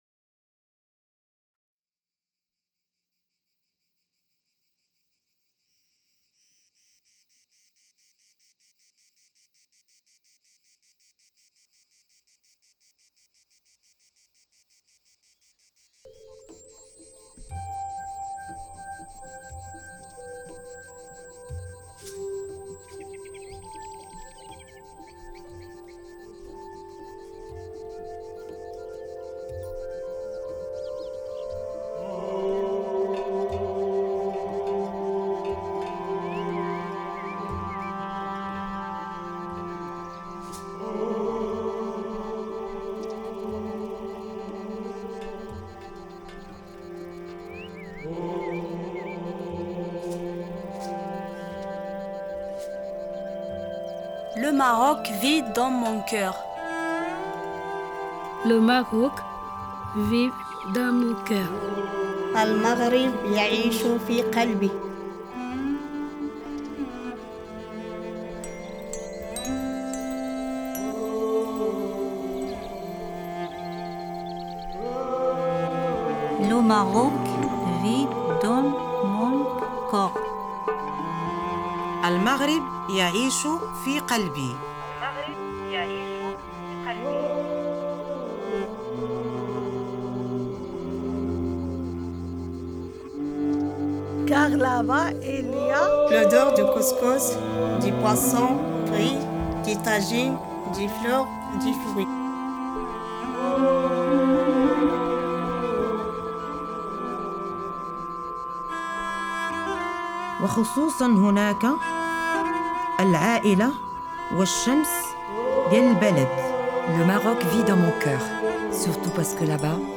DES VOIX, DES MOTS, UNE VILLE EN HARMONIE